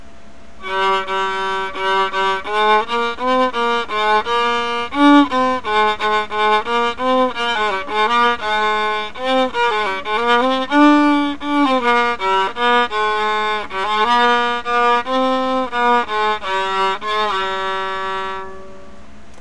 One of the pieces I use is the Gloucester Wassail song, which is a Christmas/holiday song that a lot of people would recognize when they hear it, and happens to have some useful features. Firstly, it can be played on any one string, which allows for getting in some practice on any particular string that maybe gives you a little trouble. Secondly, if you use a few embellishments with it, it gives the opportunity for practising some slurs and some sorta fast finger drops to practice getting groups of short notes so you can play them clean and quick.
Also it dates back to the medieval period, so it can be done with a medieval sort of feel or dressed up a bit to make it a bit more baroque